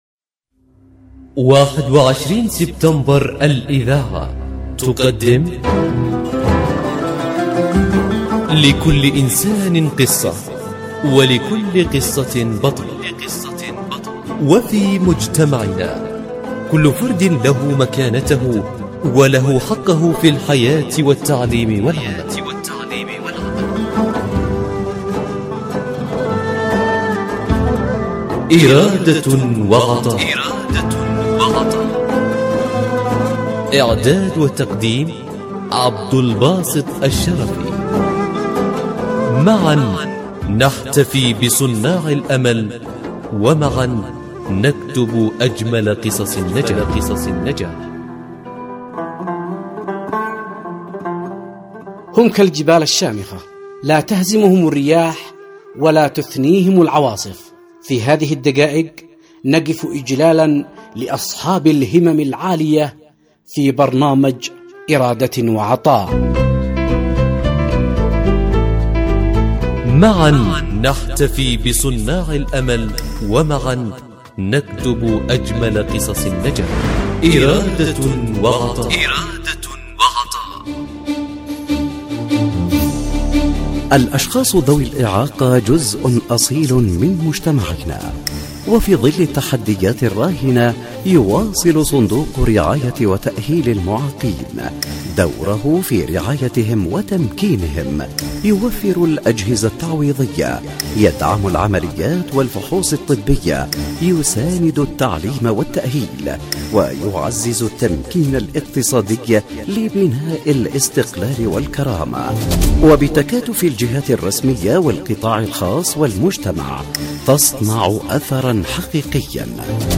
برنامج “إرادة وعطاء” يأخذكم في رحلة إذاعية قصيرة ، نستكشف خلالها عالماً مليئاً بالتحدي والإصرار. نسلط الضوء على قصص ملهمة لأشخاص من ذوي الهمم، أثبتوا أن الإعاقة لا تحد من العطاء بل تزيده قوة وإبداعاً.